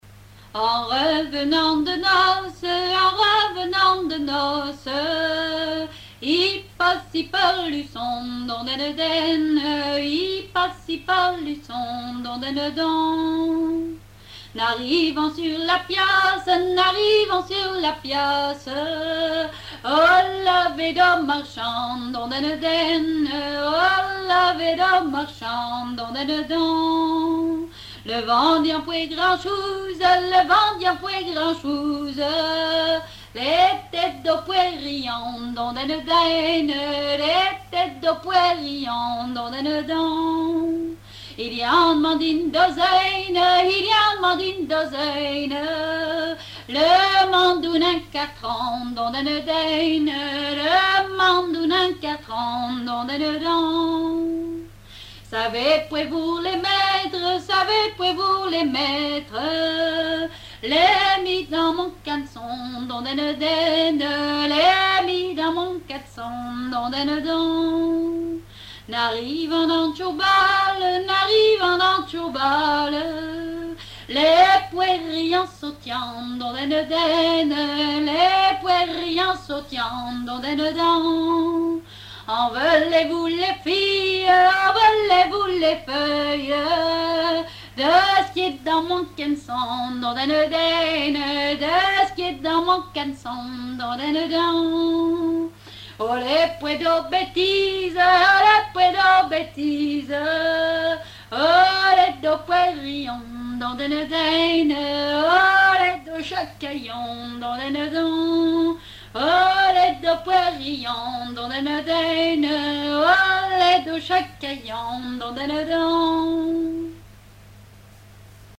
répertoire de chansons traditionnelles
Pièce musicale inédite